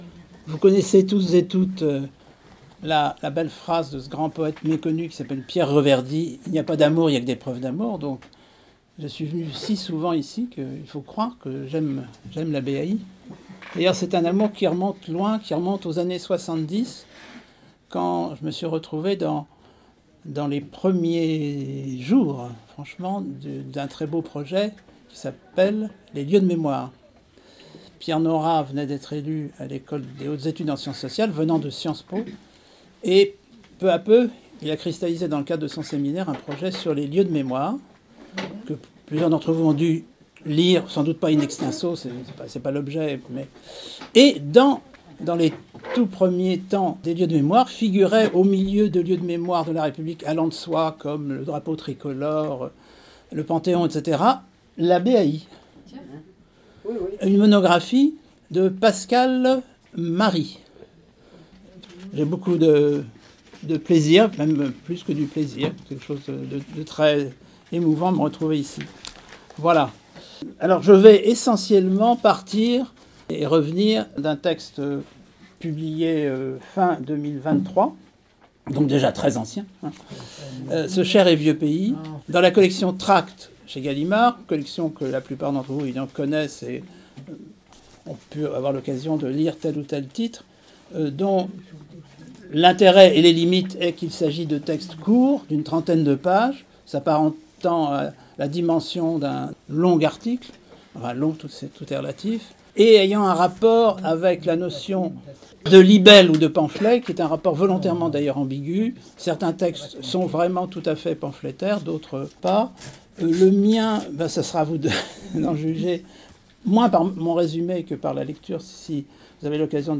Conférence du 22 mars 2025 : Pascal Ory présente “Ce Cher et vieux pays” - Bibliothèque des Amis de l'Instruction